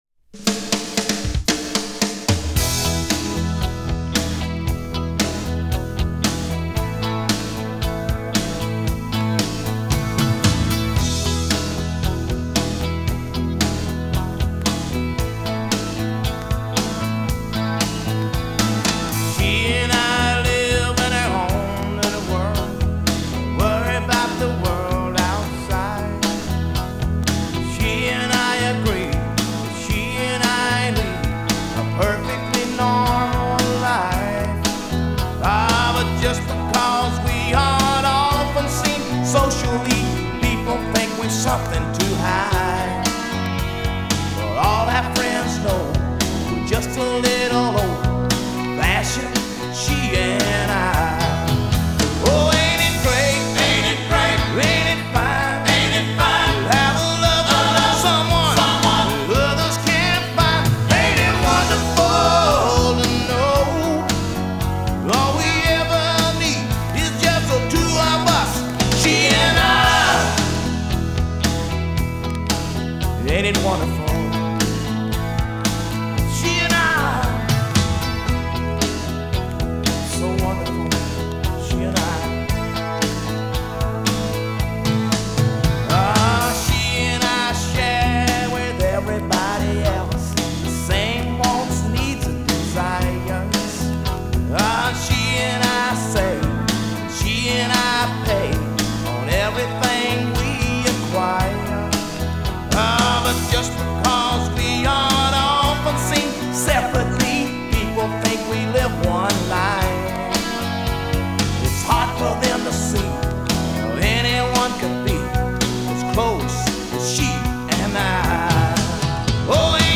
американская кантри-рок-группа.